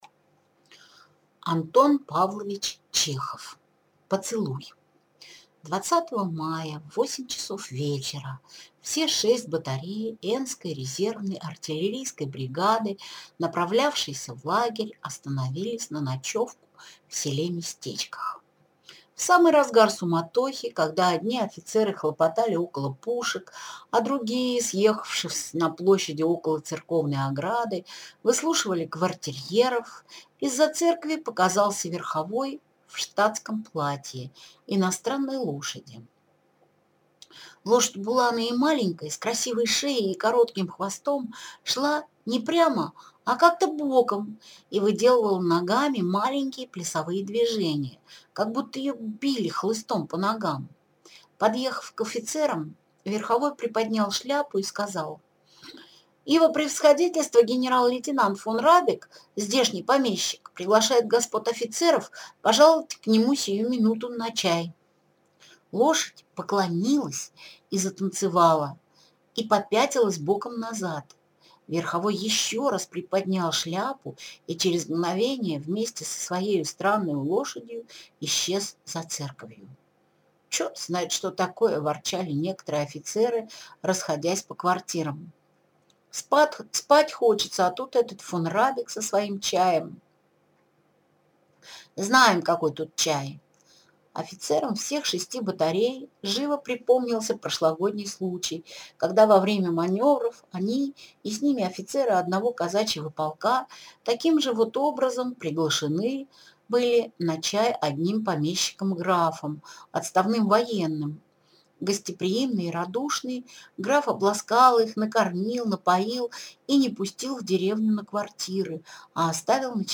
Аудиокнига Поцелуй | Библиотека аудиокниг